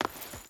Stone Chain Run 3.ogg